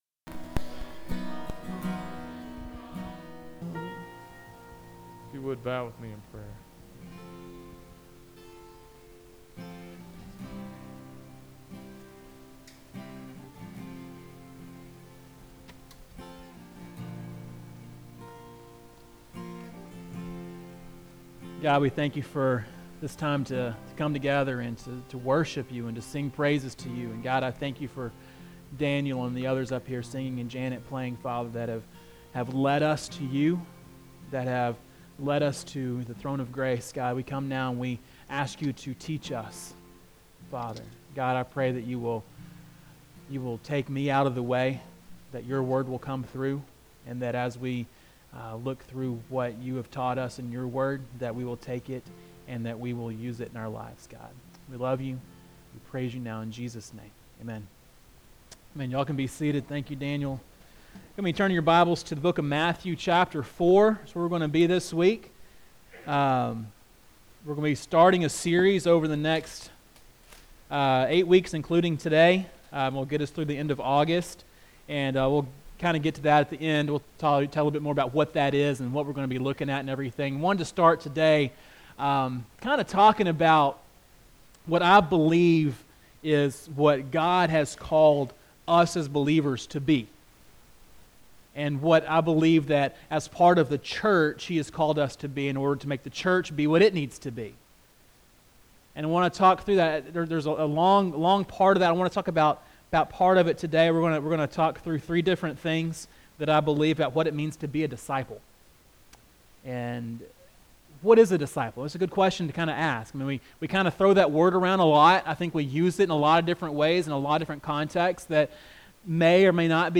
Sermons - First Baptist Church Corning